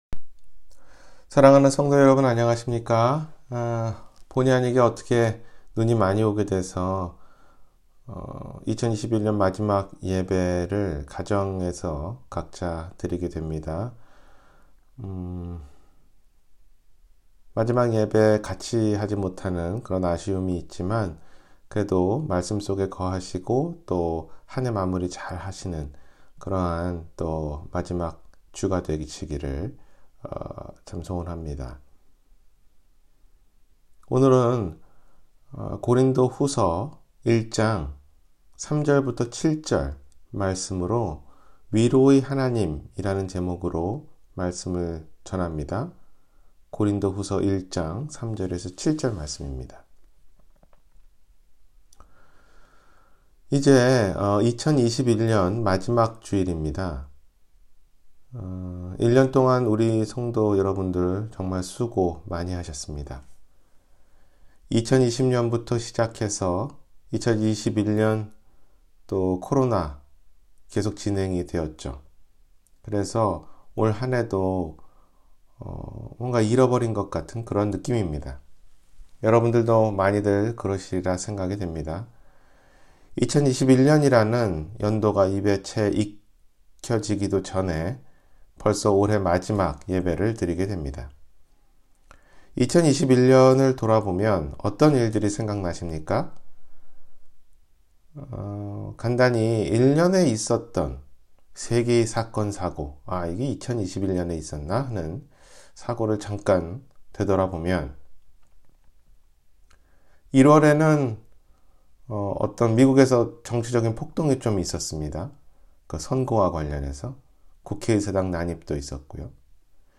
위로의 하나님 – 주일설교